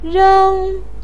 rēng to throw / to throw away
reng1.mp3